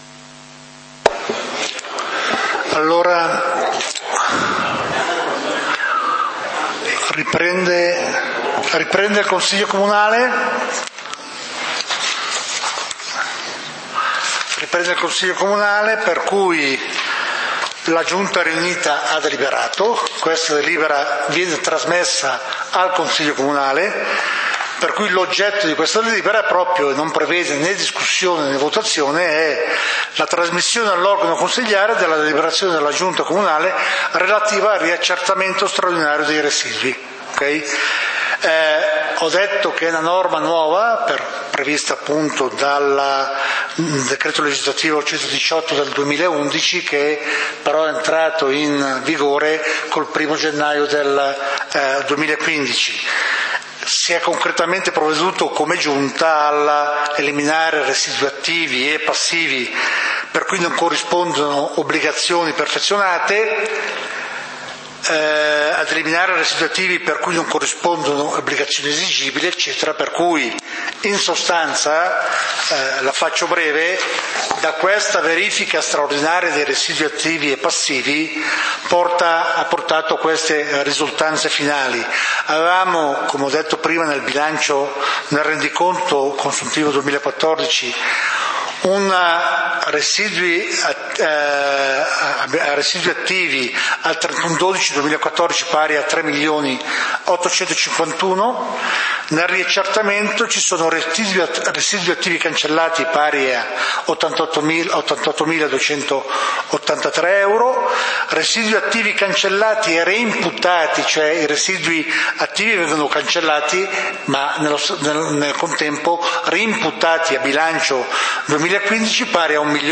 Consiglio comunale di Valdidentro del 21 Maggio 2015